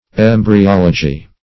Embryology \Em`bry*ol"o*gy\, n. [Gr.